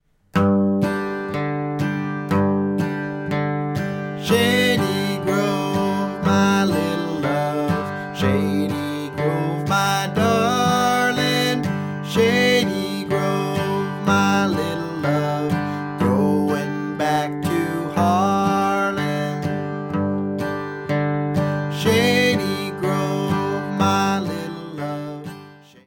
Slow version, chorus only (key of G)